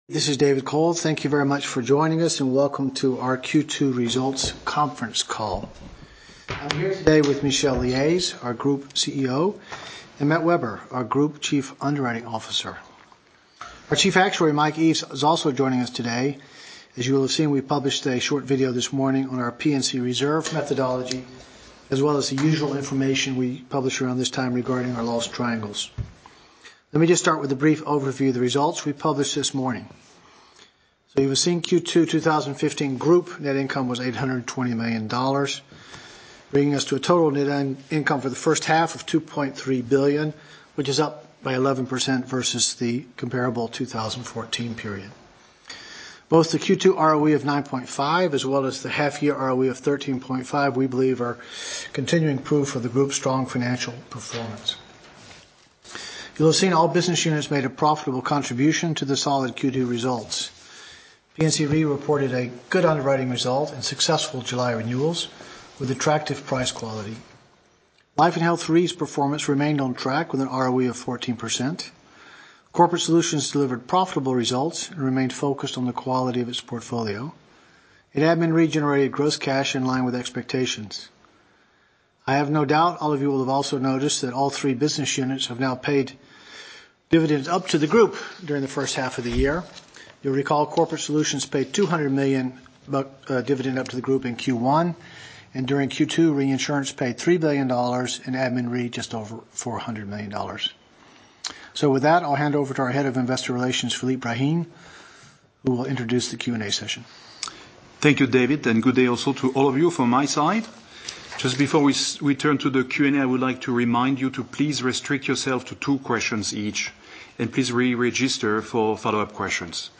Analysts Conference call recording
2015_q2_qa_audio.mp3